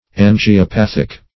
Search Result for " angiopathic" : The Collaborative International Dictionary of English v.0.48: angiopathic \an`gi*o*path"ic\ ([a^]n`j[i^]*[-o]*p[a^]th"[i^]k), a. (Med.) Of or pertaining to angiopathy.
angiopathic.mp3